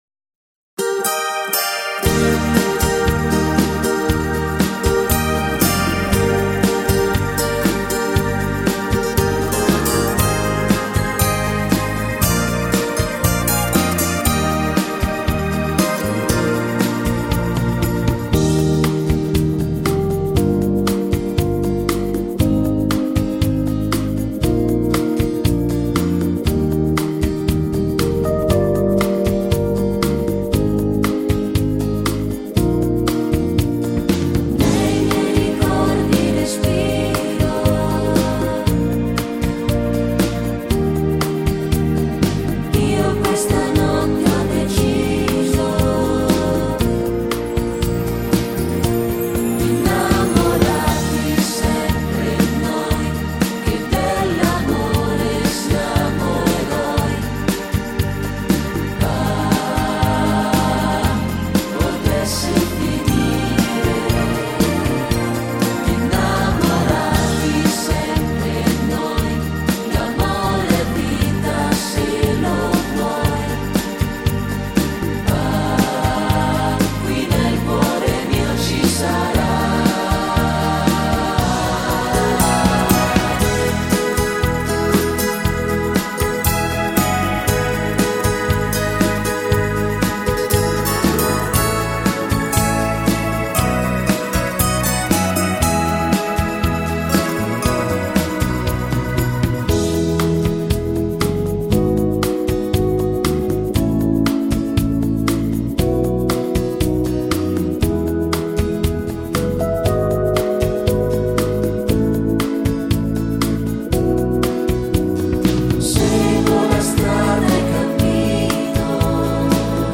Base in tonalità femminile